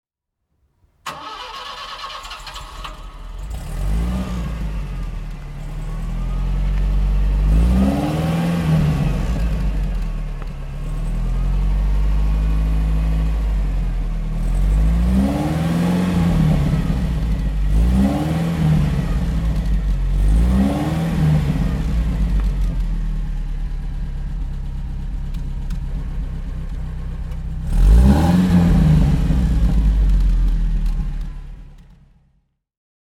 Citroën DS 21 Pallas (1971) - Starten und Leerlaufgeräusch